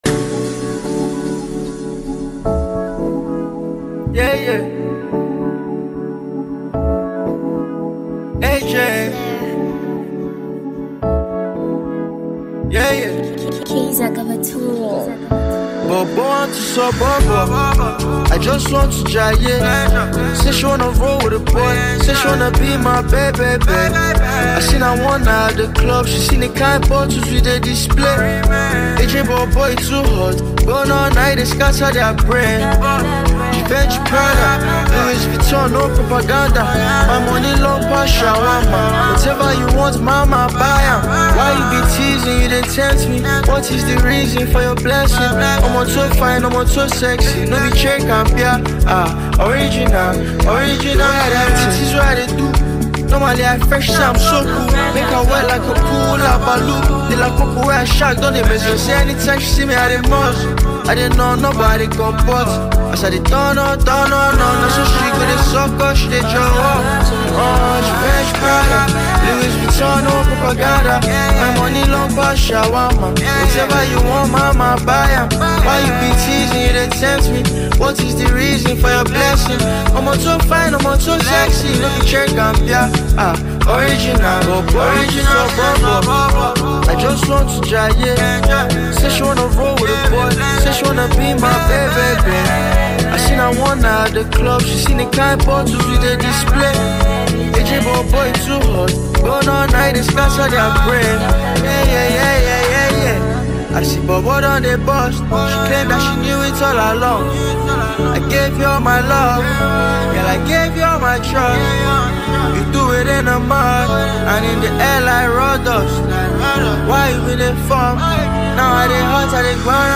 Home » South African Music